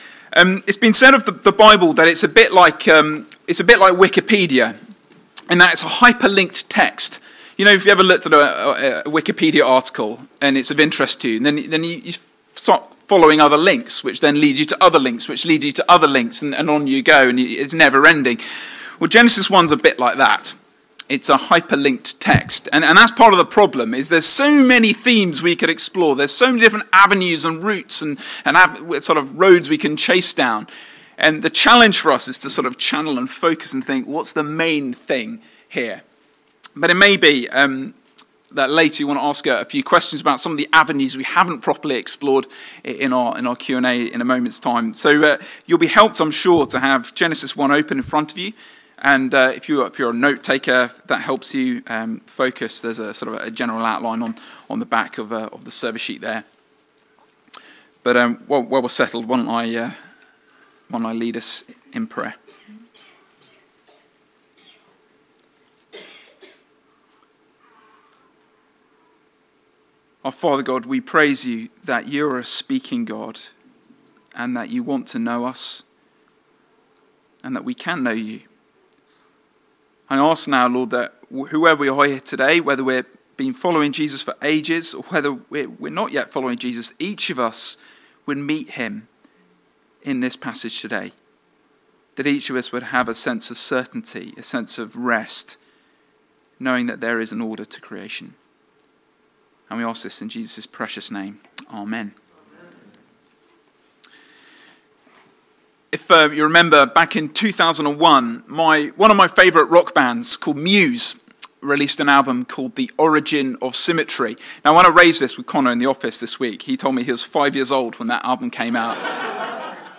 This is the first sermon in our Origin Story series looking at Genesis. This sermon focused on verses 1:1 to 2:3 and had 5 main points: